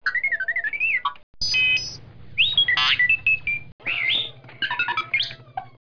An assortment of Astromech sounds